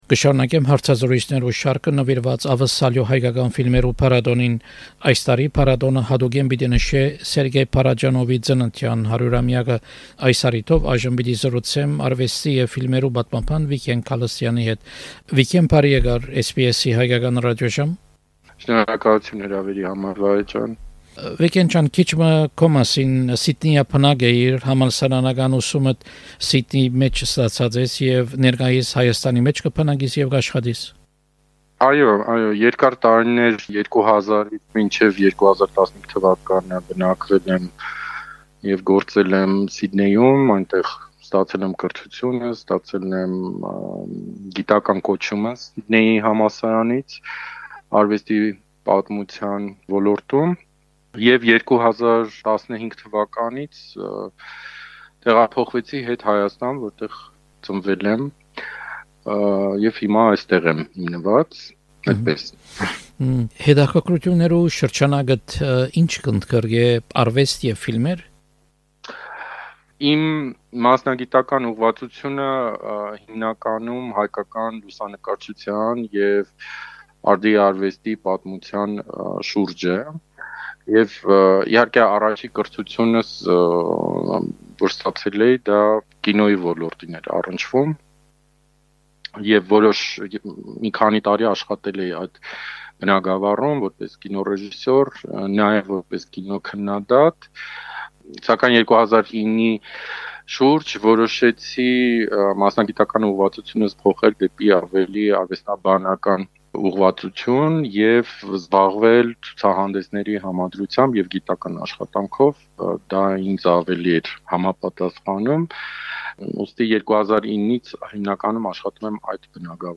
Art and film historian